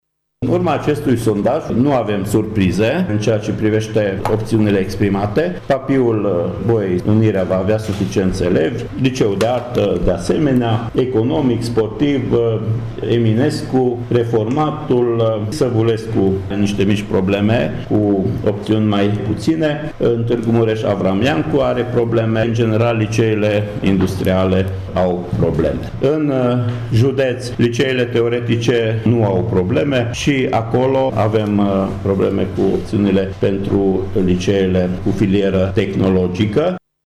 Inspectorul general școlar Ștefan Someșan a declarat astăzi, într-o conferință de presă, că atât Colegiile Nationale Papiu și Unirea, cât si liceele Bolyai și cel de Artă vor avea suficienți elevi din toamnă.